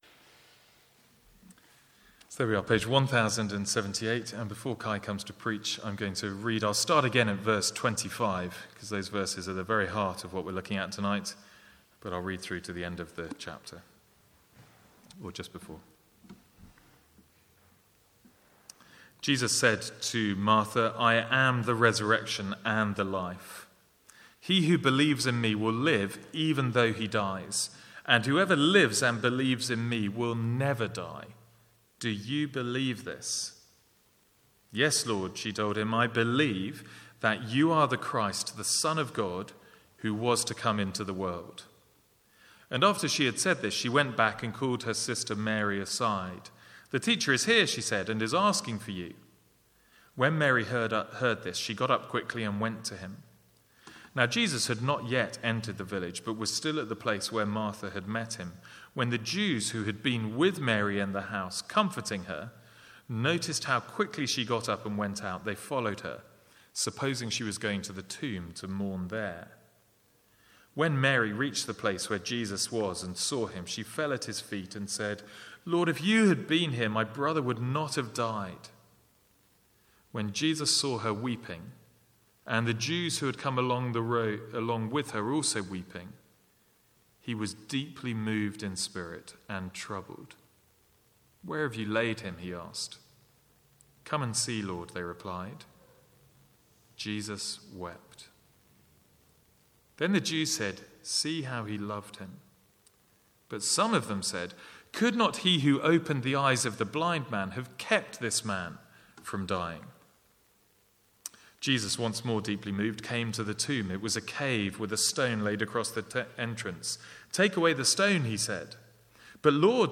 Sermons | St Andrews Free Church
From the Easter Sunday evening service 2014.